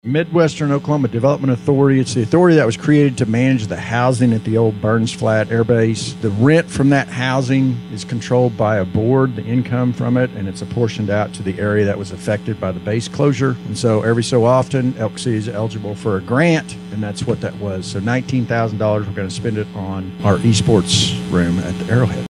City manager Tom Ivester says the money will be used to purchase e-sports equipment at the Arrowhead Center.